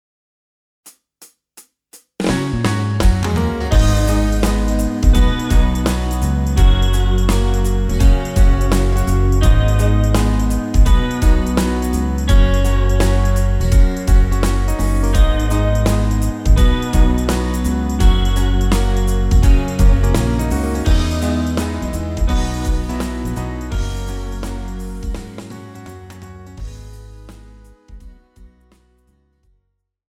KARAOKE/FORMÁT:
Žánr: Rock
BPM: 168
Key: G#m
MP3 ukázka